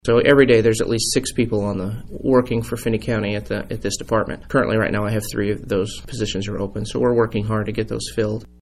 swords-one.mp3